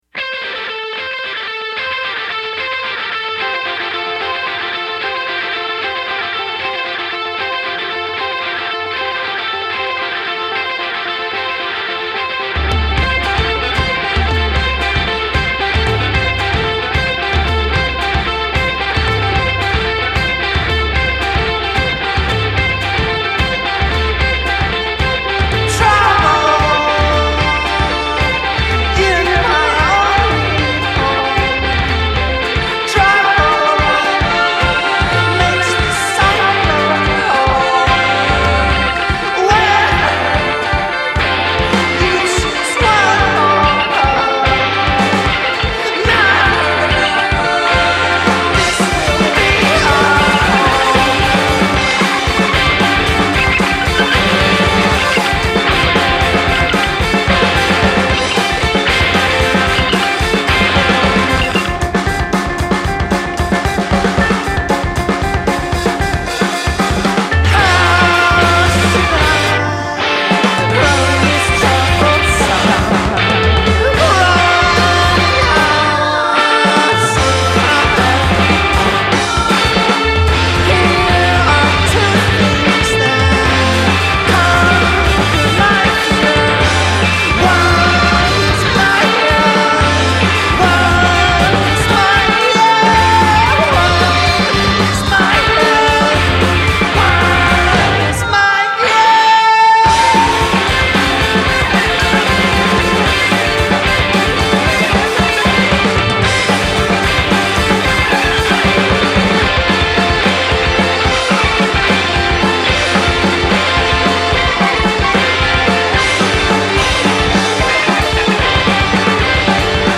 Bklynoiserockers